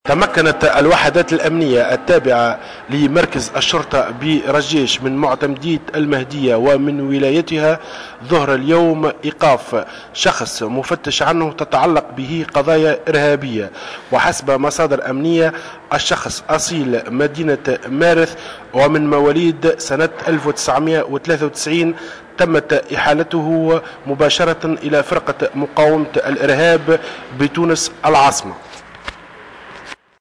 مراسلنا في المهدية